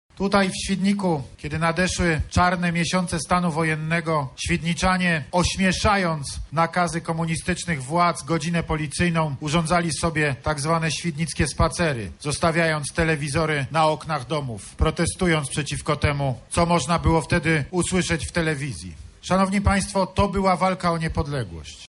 Prezydent złożył kwiaty i wygłosił okolicznościowe przemówienie.